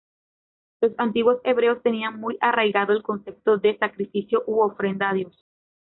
Pronounced as (IPA) /oˈfɾenda/